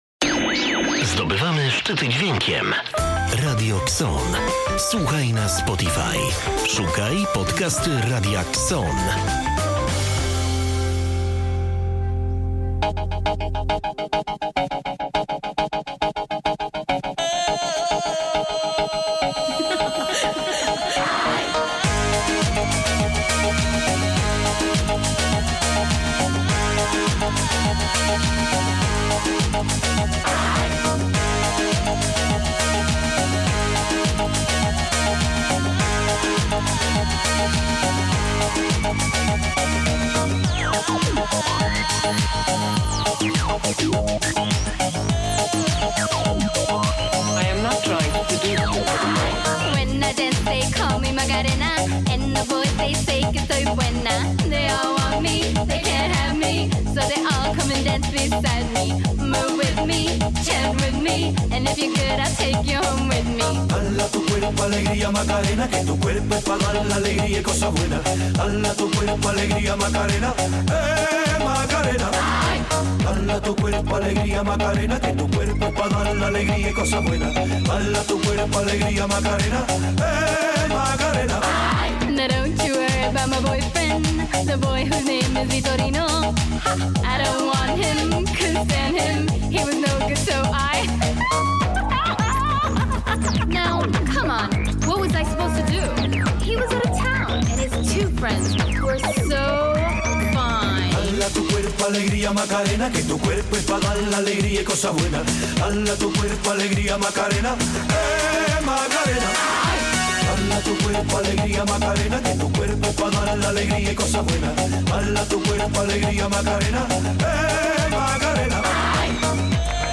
Jazz, Rapp, Dance, archiwalna audycja Marchewkowego Studia wprowadzi was w muzyczne nastroje. Tym razem na tapetę bierzemy temat MUZYKI.